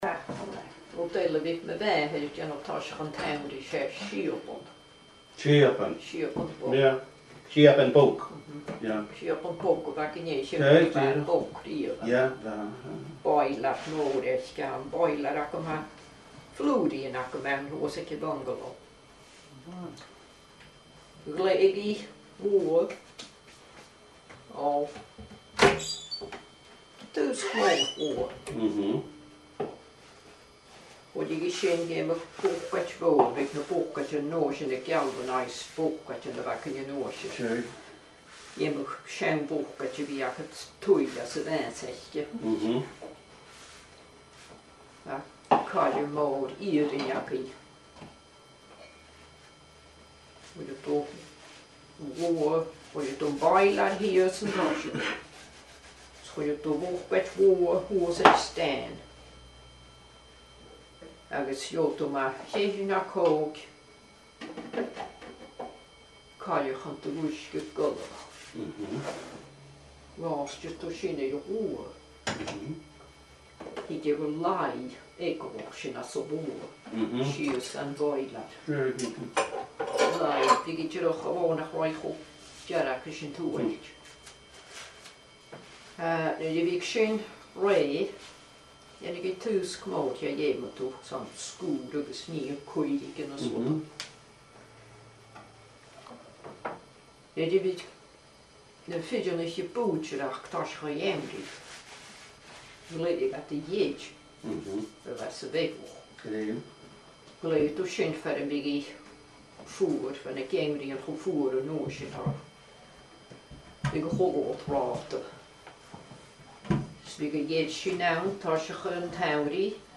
Fear-agallaimh